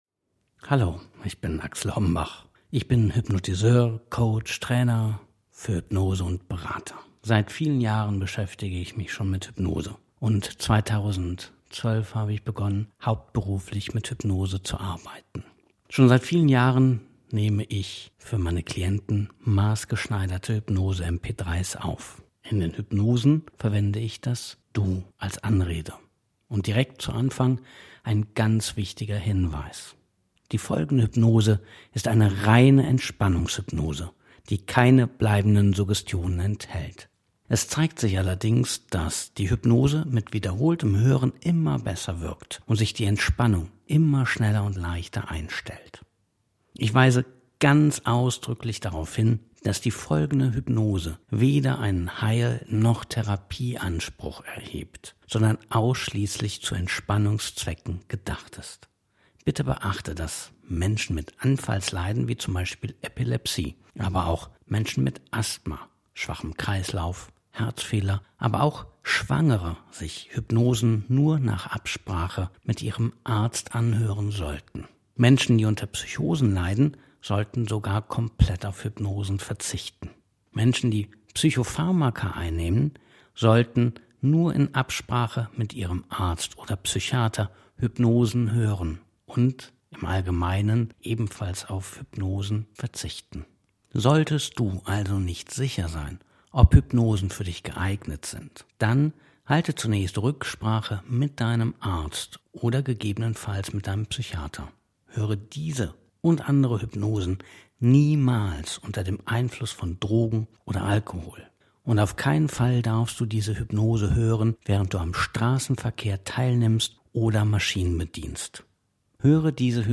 noj-entspannungshypnose-01-frei